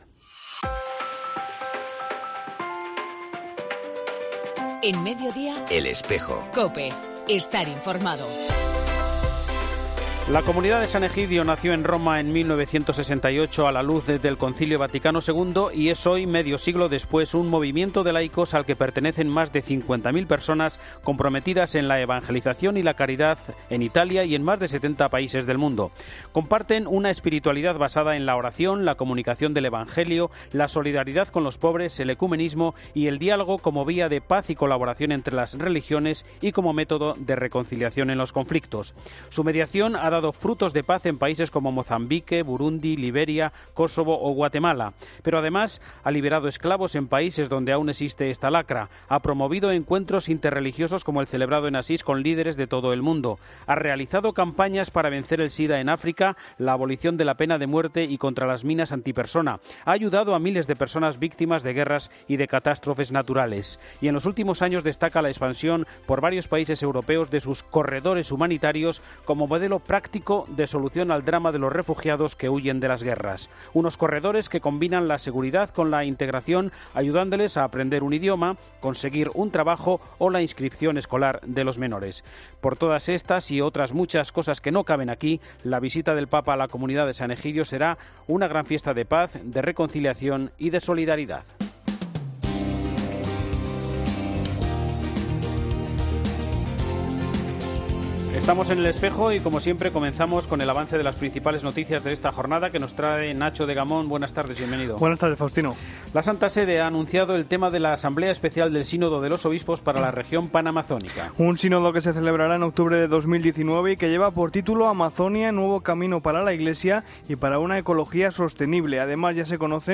La entrevista de hoy en El Espejo